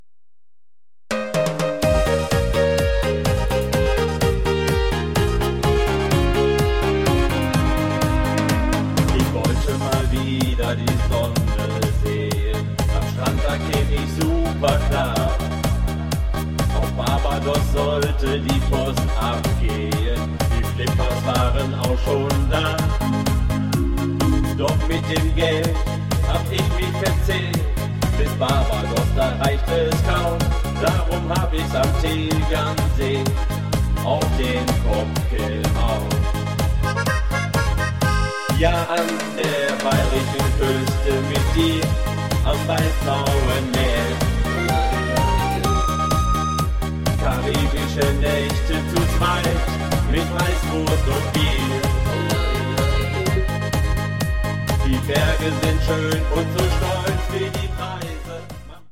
--- Schlager ---